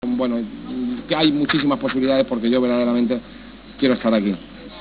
Declaraciones de Caparrós sobre su continuidad en el equipo tras el ascenso a segunda división.